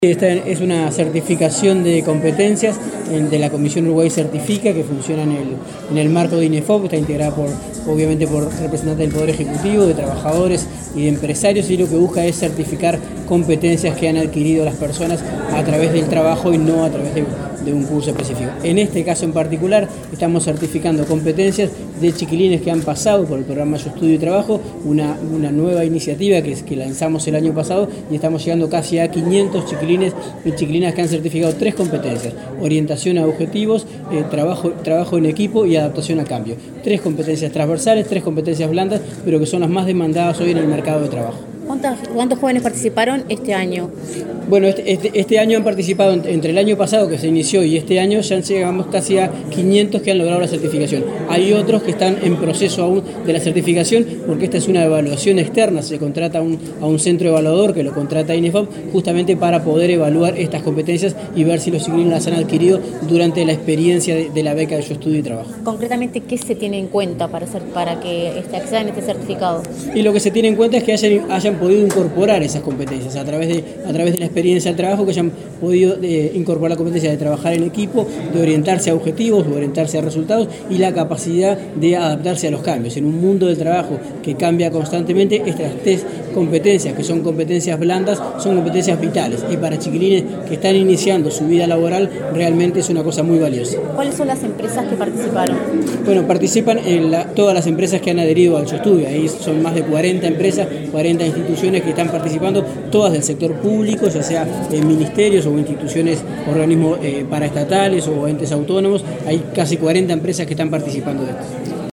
Declaraciones del director nacional de Empleo